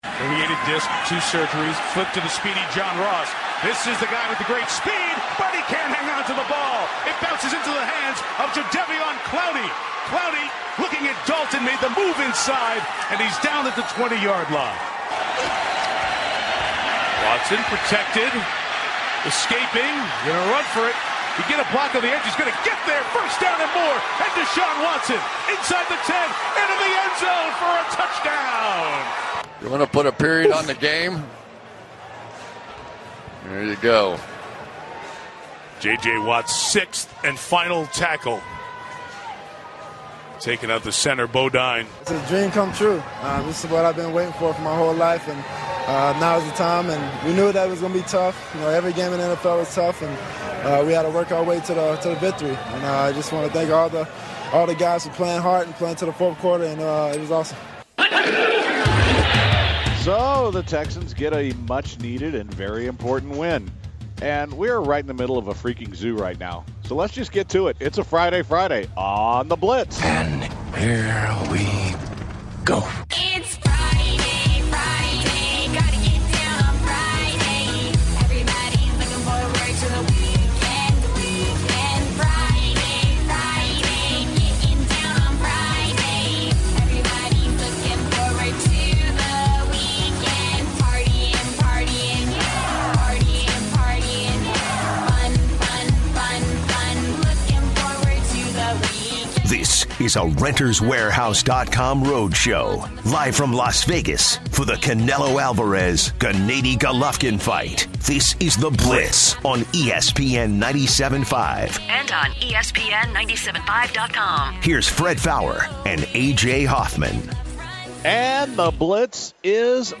The guys are live from Las Vegas for the Canelo/GGG fight and they interview Roy Jones Jr. and Jim Lampley.